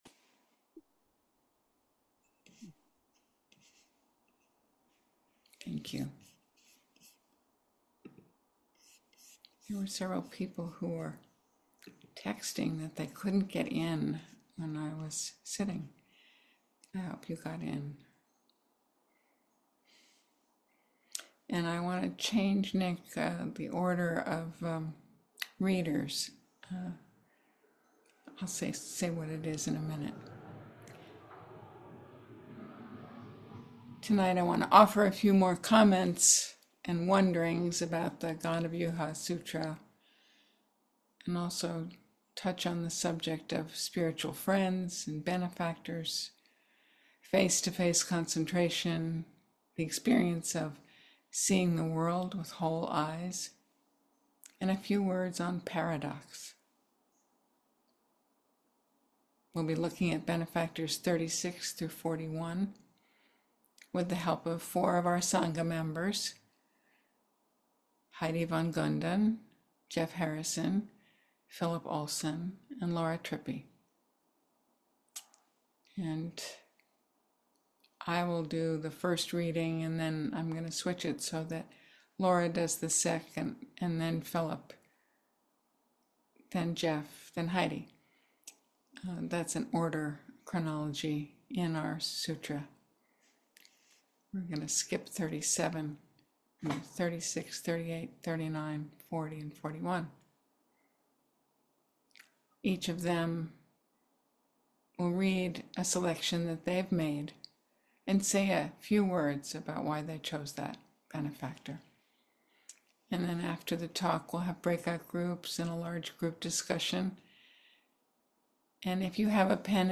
Talk 11